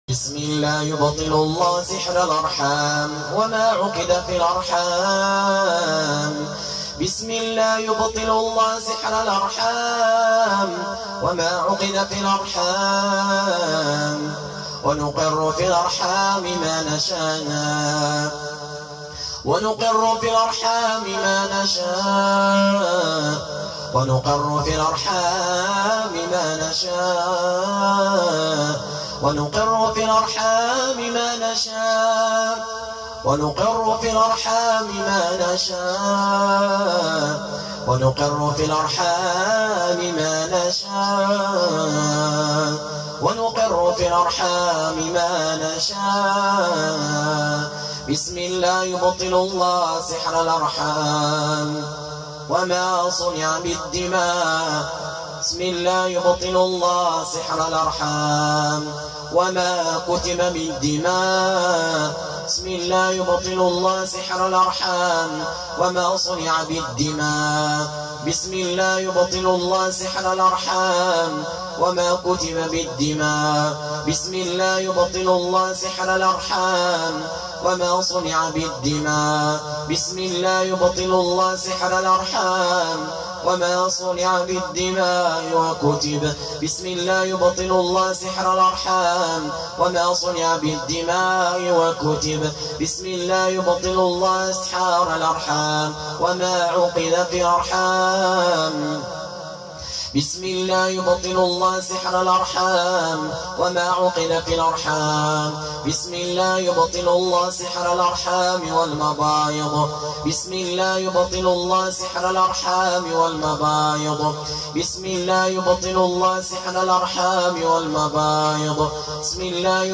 জরায়ুতে থাকা জ্বীন এবং যাদু ধ্বংসের রুকইয়াহ— Ruqyah for Burn Jinn and sihr Inside Uterus
জরায়ুতে-থাকা-জ্বীন-এবং-যাদু-ধ্বংসের-রুকইয়াহ—-Ruqyah-for-Burn-Jinn-and-sihr-Inside-Uterus.mp3